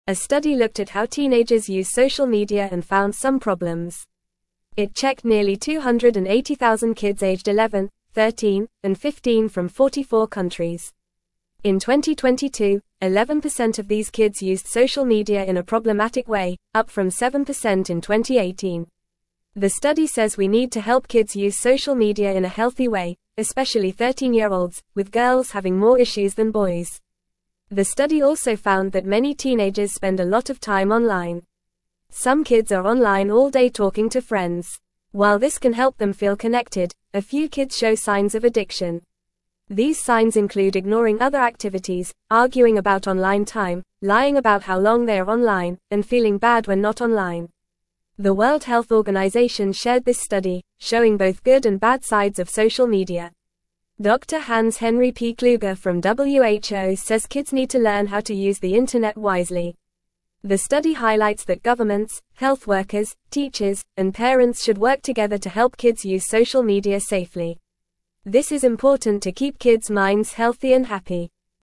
Fast
English-Newsroom-Lower-Intermediate-FAST-Reading-Kids-Using-Social-Media-More-Since-Pandemic-Started.mp3